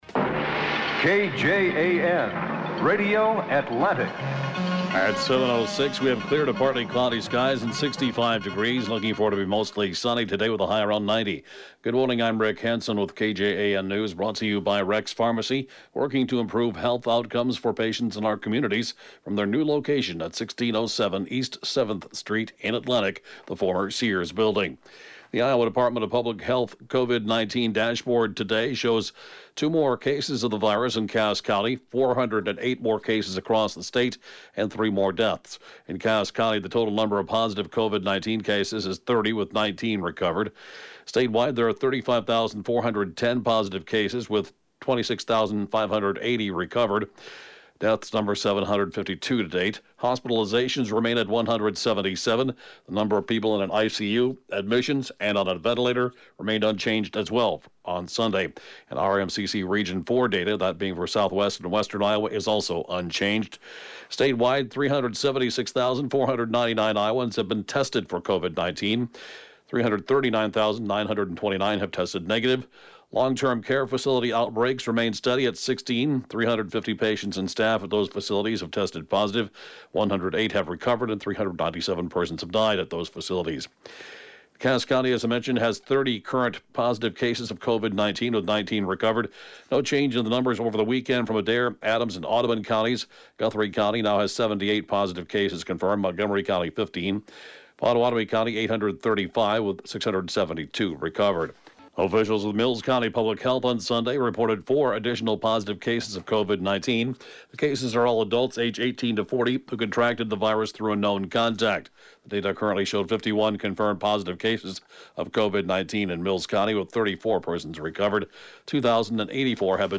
(Podcast) KJAN Morning News & Funeral report, 7/13/20